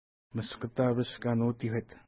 Pronunciation: miskəta:pəʃ ka:nu:ti:hkwet